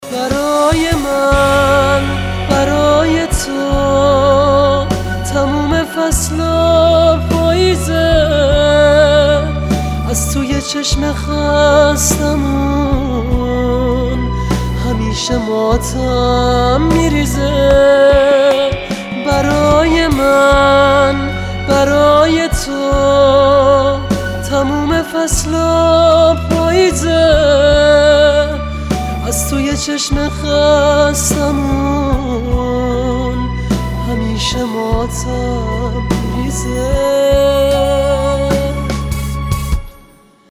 رینگتون رمانتیک و با کلام